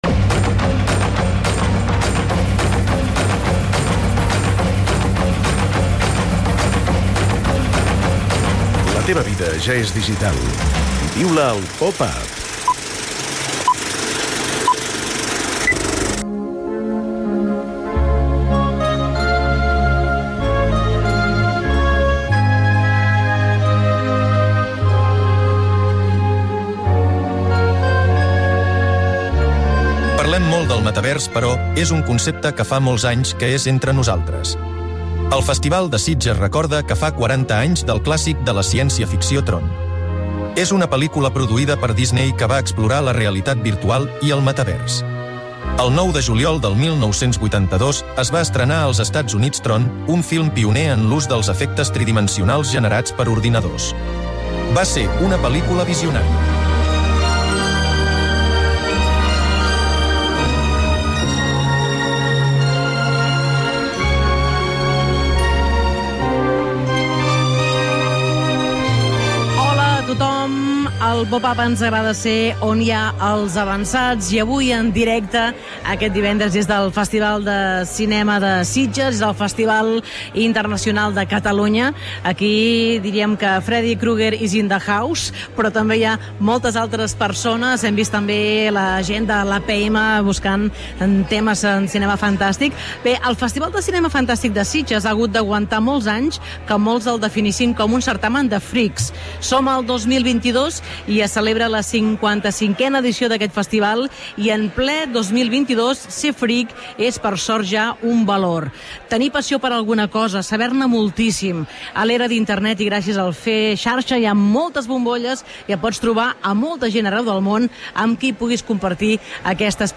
Avui el "Popap" s'ha traslladat a Sitges, on es fa el 55è Festival Internacional de Cinema Fantàstic de Catalunya.